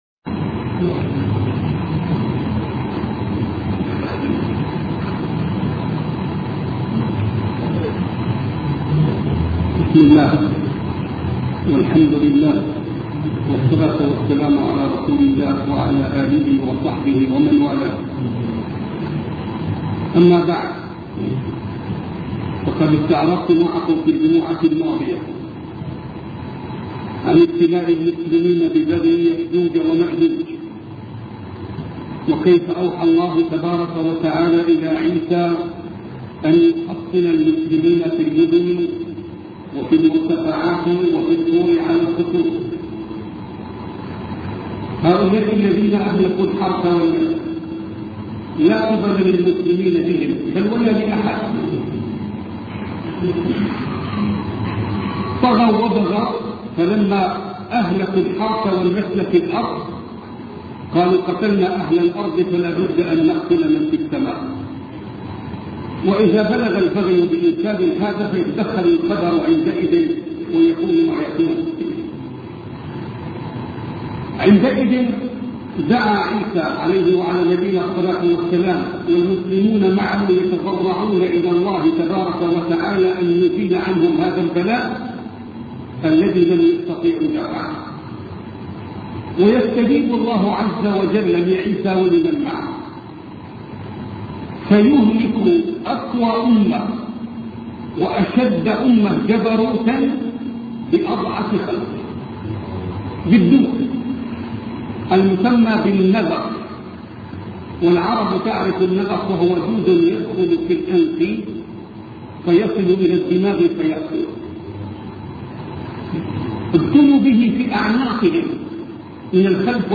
سلسلة محاطرات